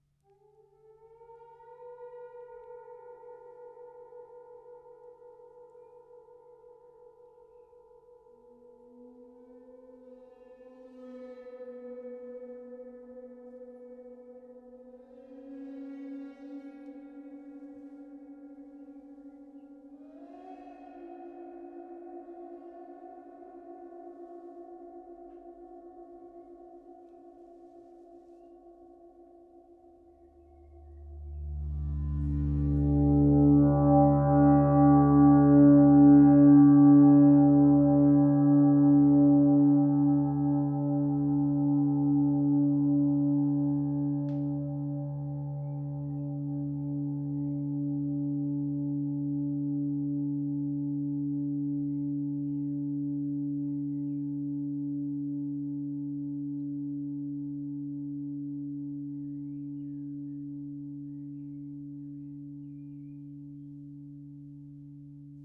Gong Samatha R. Bonneau • 96 cm
D’un diamètre généreux de 96 cm, ce gong développe une richesse harmonique impressionnante avec des sons profonds.
Chaque frappe révèle des paysages sonores immersifs, invitant à une exploration sensorielle et à un véritable lâcher-prise.
• Sonorité profonde et enveloppante
Extrait frictions